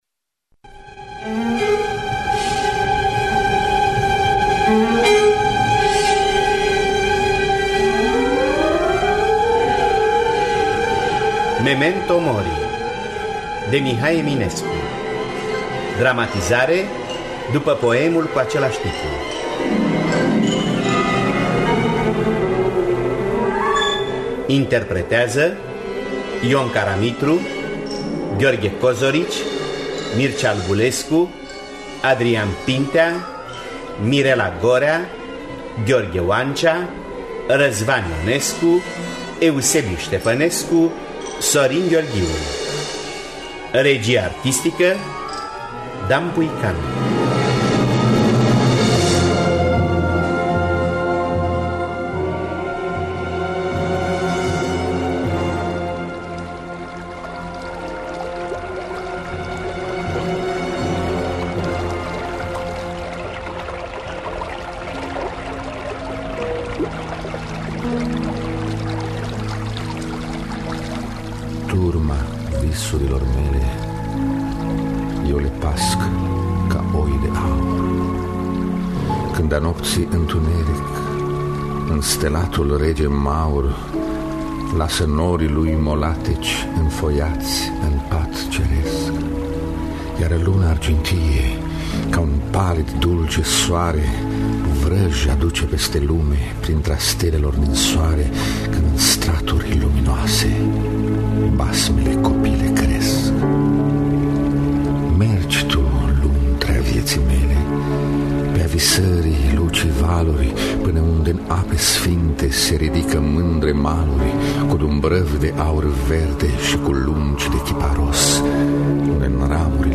Memento mori de Mihai Eminescu – Teatru Radiofonic Online